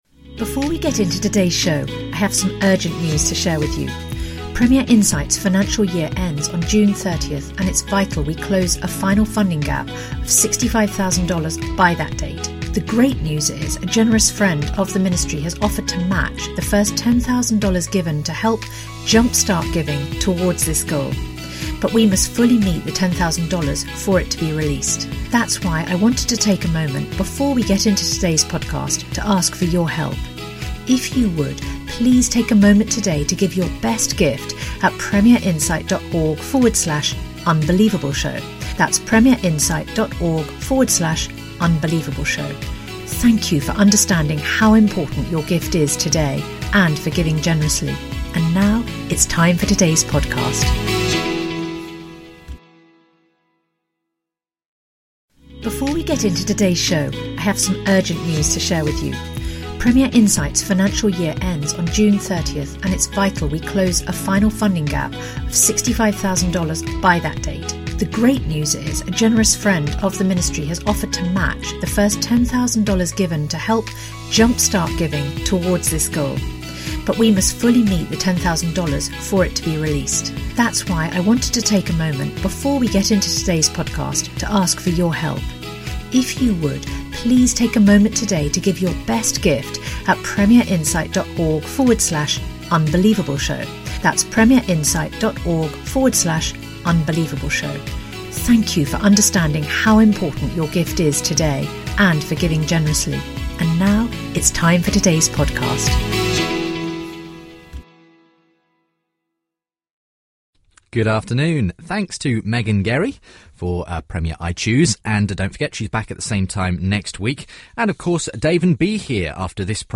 A fascinating discussion.